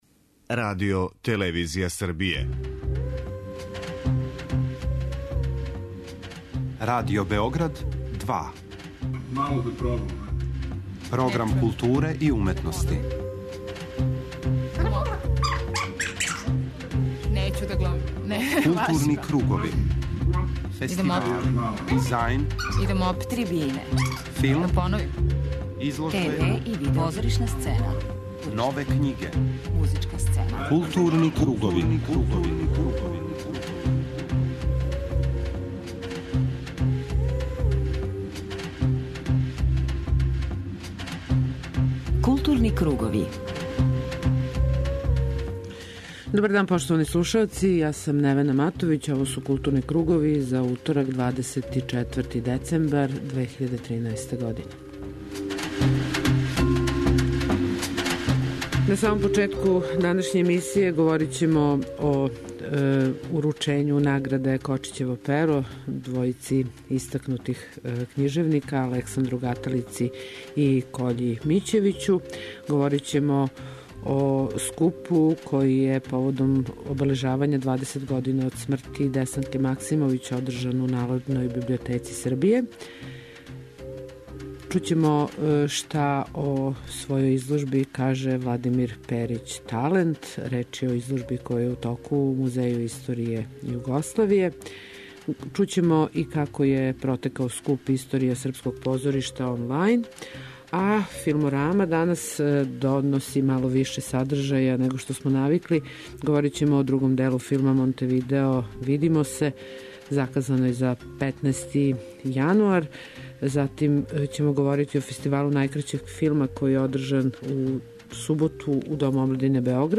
У првом сату емисије бележимо најзначајније догађаје из културе, а из садржаја Филмораме издвајамо интервју са редитељем Срђаном Карановићем, смотру домаћих краткометражних филмова 'Најкраћи дан', представљање Галерије 'Рисим' из Чачка и најаву премијере филма 'Монтевидео, видимо се', која је заказана за 15. јануар 2014. године.